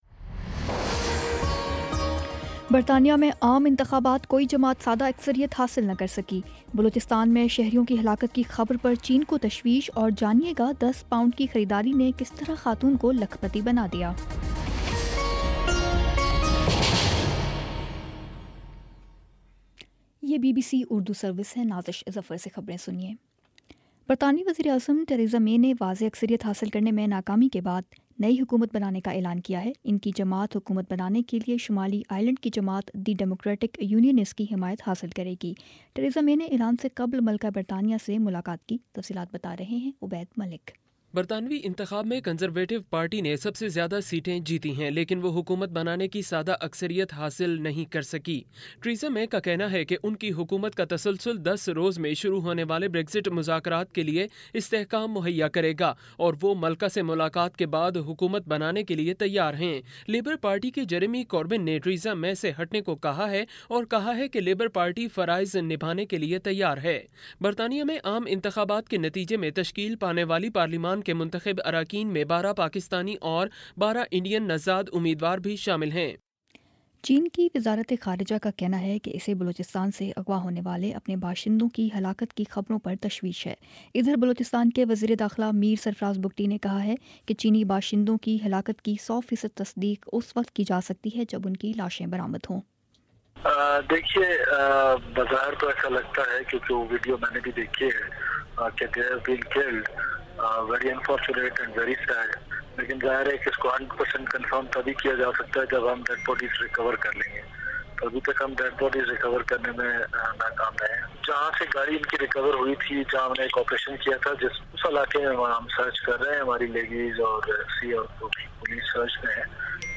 جون 09 : شام چھ بجے کا نیوز بُلیٹن